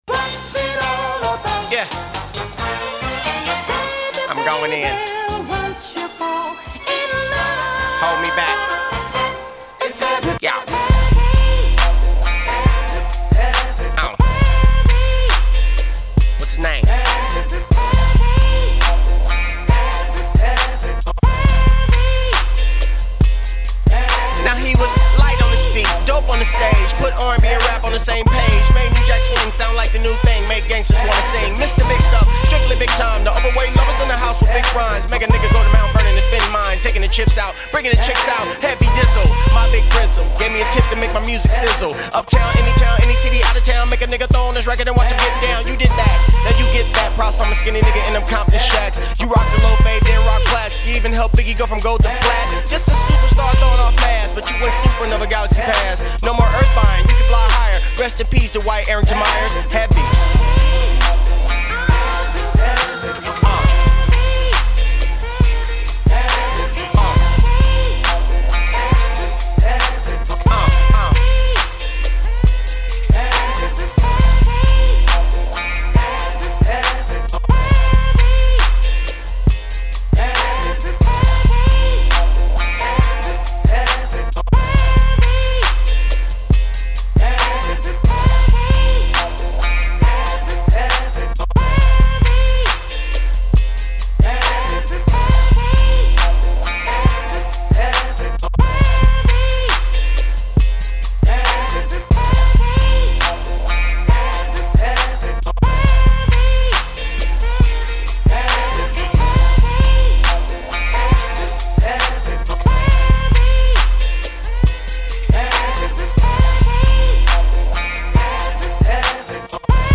NY-styled, vocal sample flip.